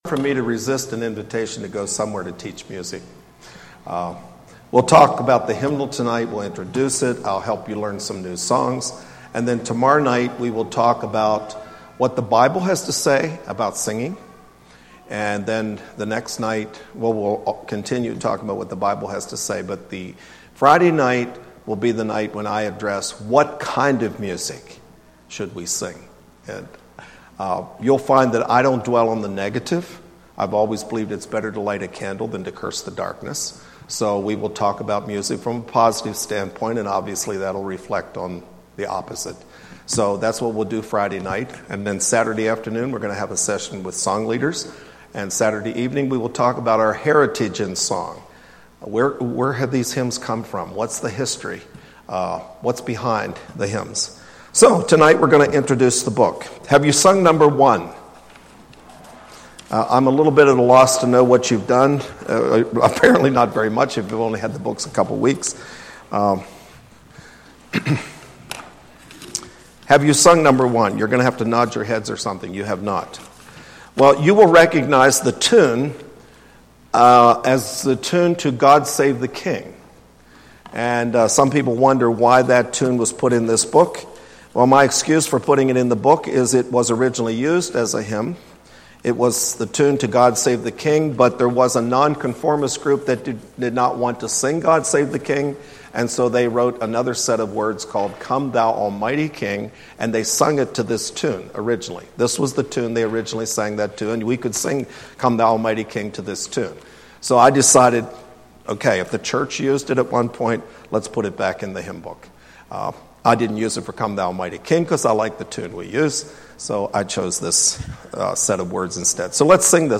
0001 Singing Hymns of the Church.mp3